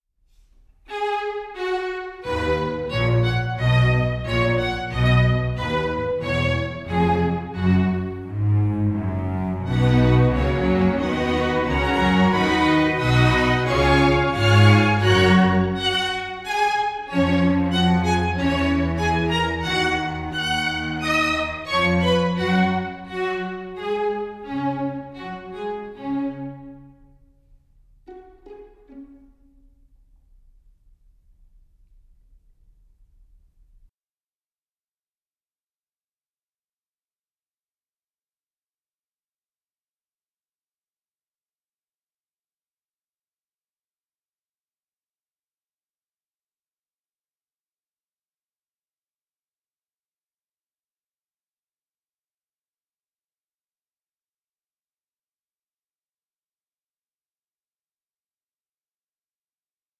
ENERGETIC AND ADVENTUROUS
string ensemble